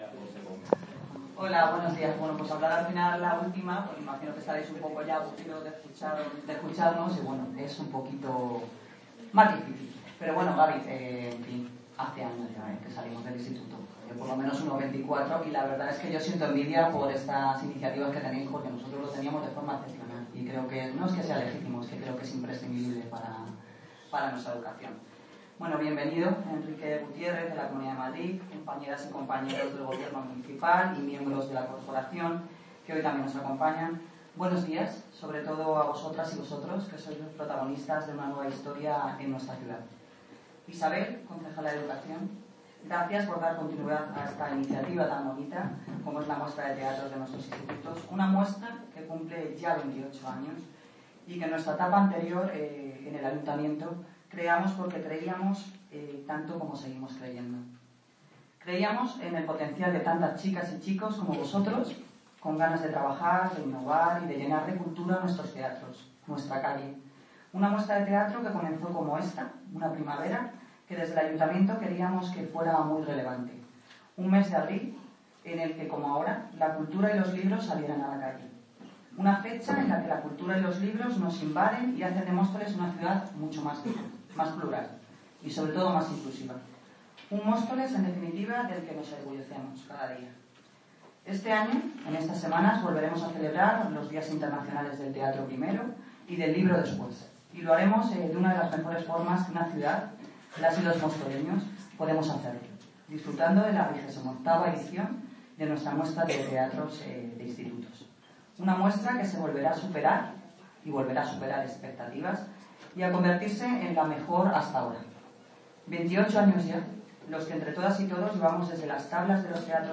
Audio - Noelia Posse (Alcaldesa de Móstoles) Sobre XXVIII Muestra de Teatro Institutos